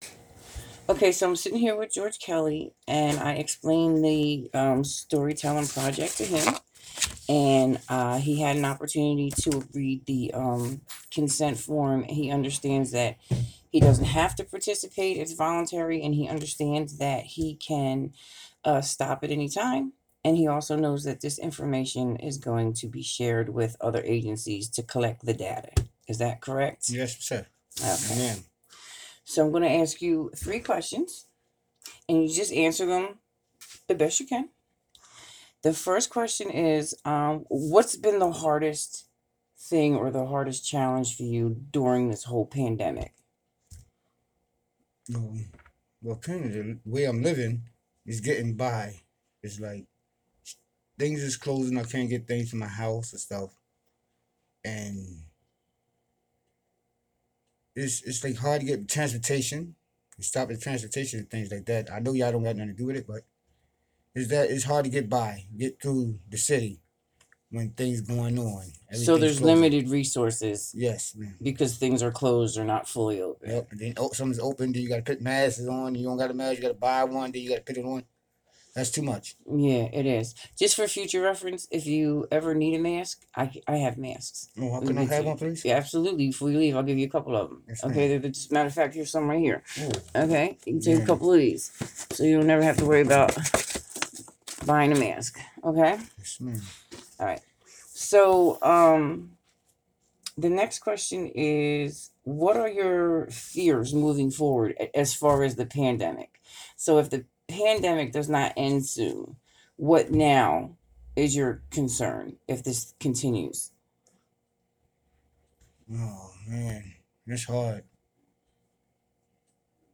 Participant 167 Community Conversations Interview